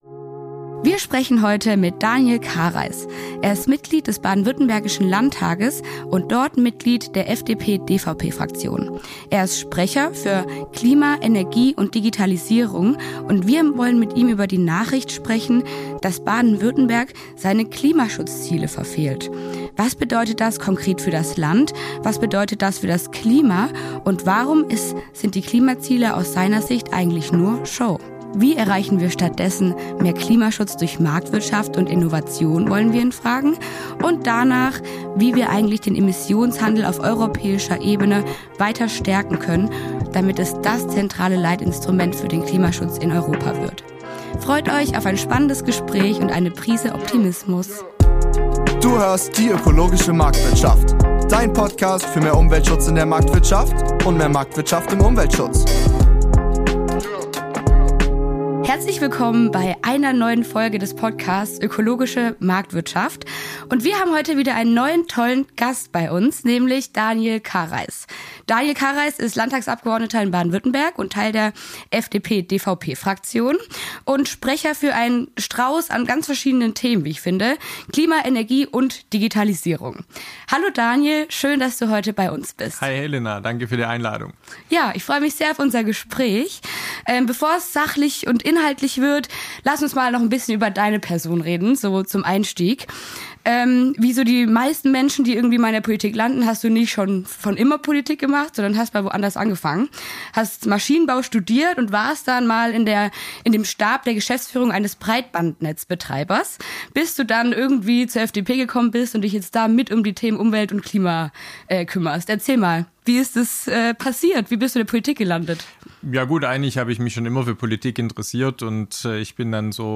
Beschreibung vor 9 Monaten Wir sprechen heute mit dem Vorsitzenden des Ausschusses für Umwelt, Klima und Energiewirtschaft sowie Sprecher für Digitalisierung und Klimaschutz der FDP/DVP-Fraktion im Landtag von Baden-Württemberg, Daniel Karrais. Daniel erklärt uns, warum er von starren Klimazielen nicht viel hält und wie wir in Baden-Württemberg Umweltschutz marktwirtschaftlicher denken können.